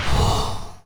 poly_shoot_skull.wav